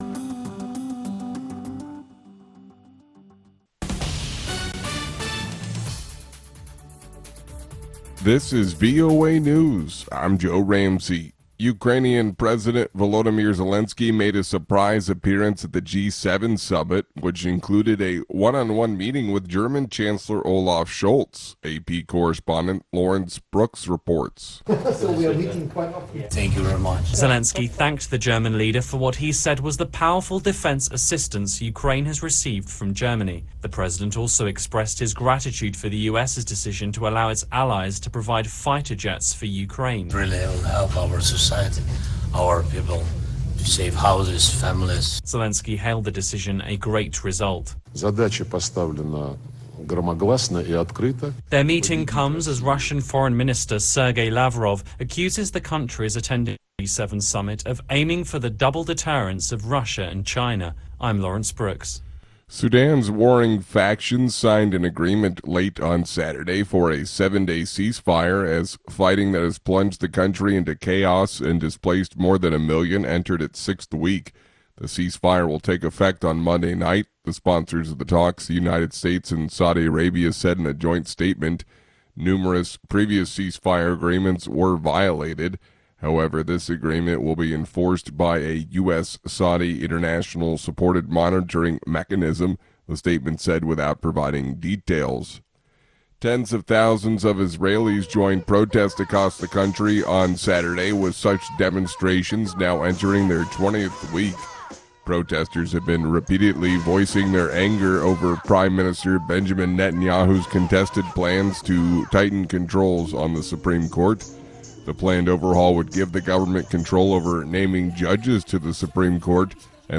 Two-Minute Newscast
We bring you reports from our correspondents and interviews with newsmakers from across the world.